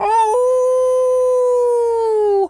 wolf_howl_05.wav